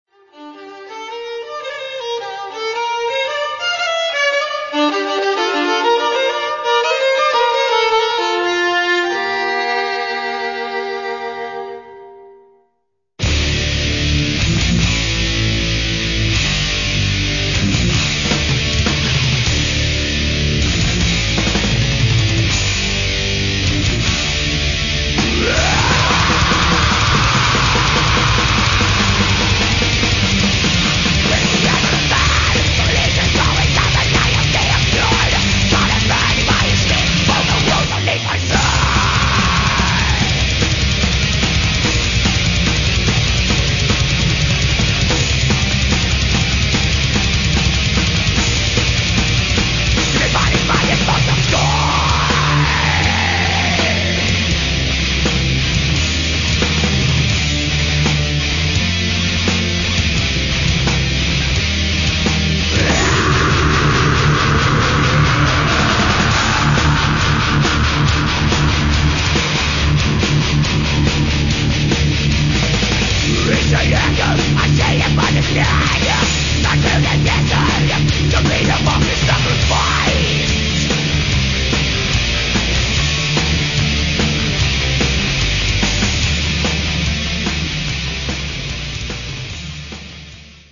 Все файлы размещены с качеством 32 Кбит/с, 22 кГц, моно